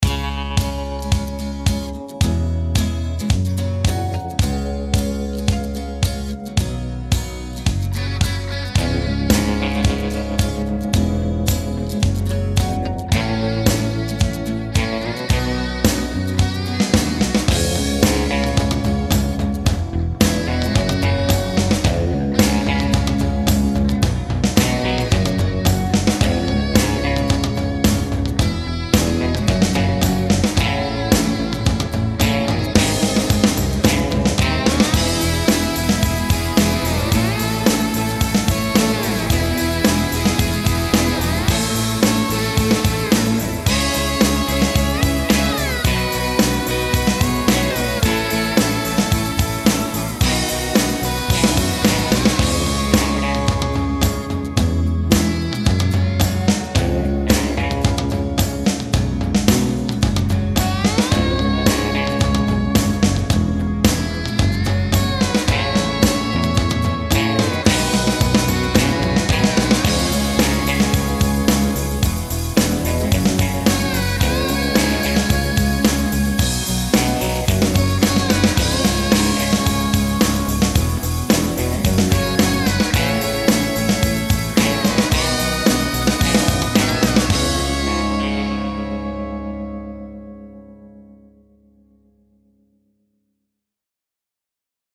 a soundtrack for a fictitious movie